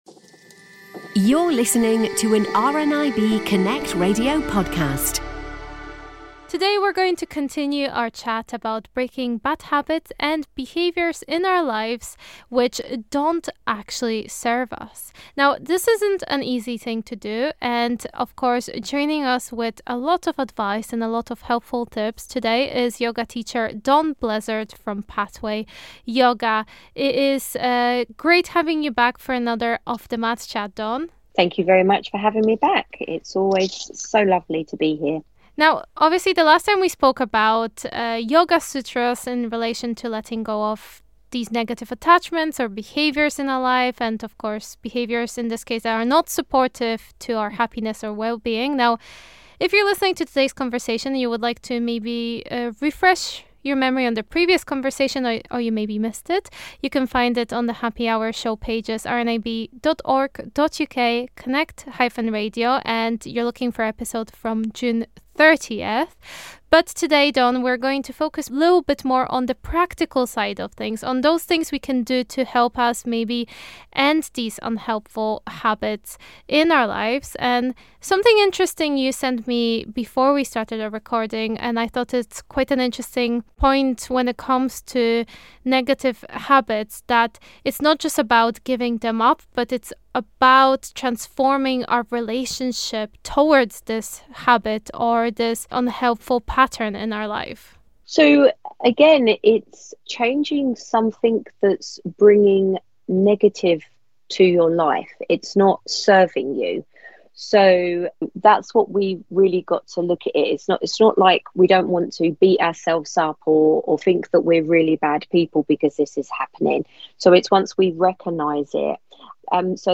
On The Happy Hour this week, we continue our conversation about breaking bad habits and behaviours in our lives.